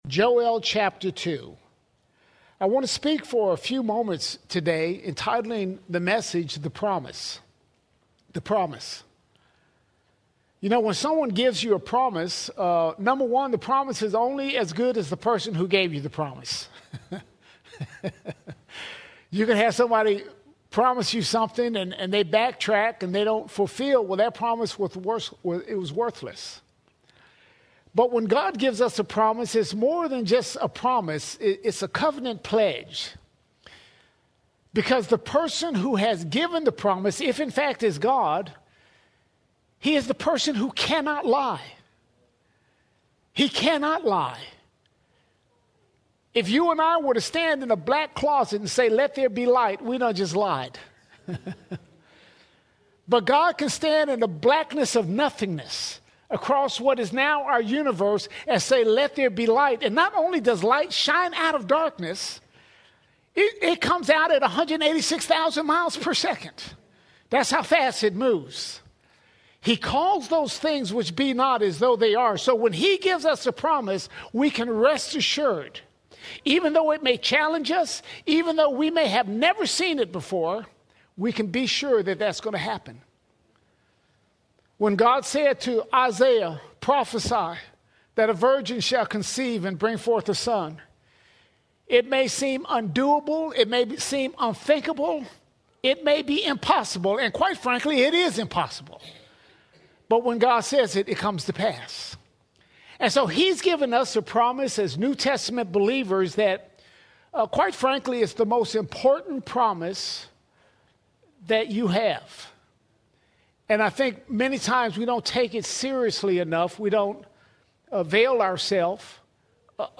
13 October 2025 Series: Sunday Sermons All Sermons The Promise The Promise In Jesus Christ, we have the promise of the Holy Spirit!